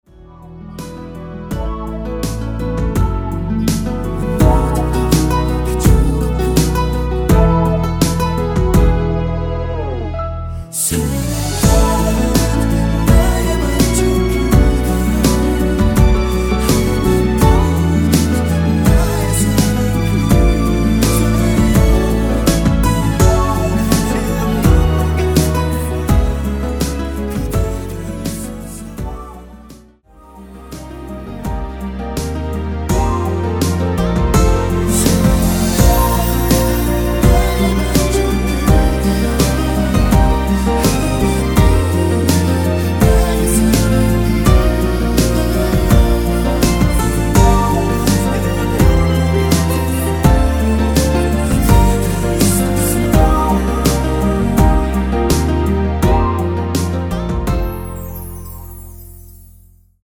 원키 코러스 포함된 MR 입니다.(미리듣기 참조)
앞부분30초, 뒷부분30초씩 편집해서 올려 드리고 있습니다.
중간에 음이 끈어지고 다시 나오는 이유는